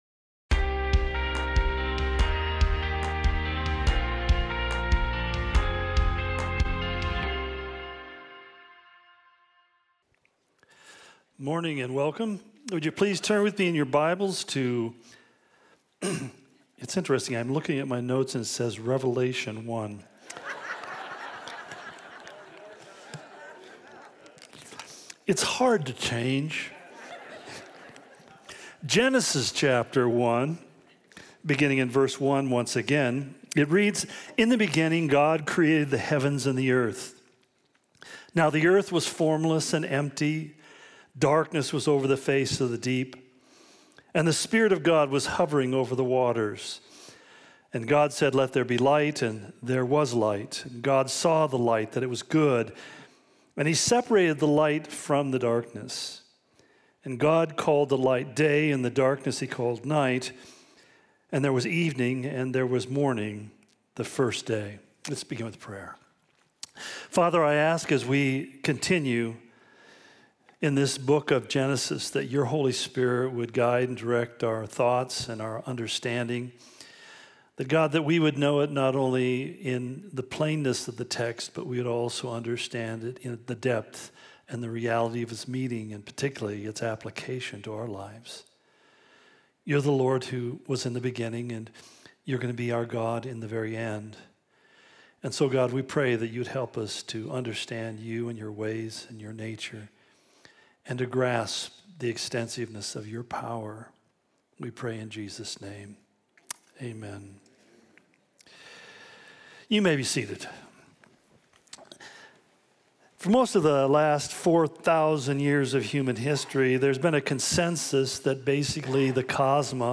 The Origin Of Everything - Part 2 Day One Calvary Spokane Sermon Of The Week podcast